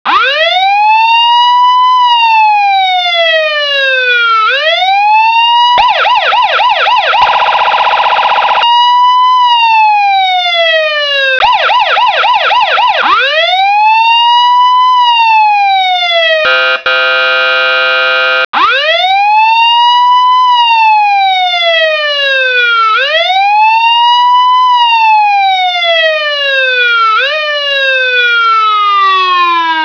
Index of /Sirens
sirenmix.wav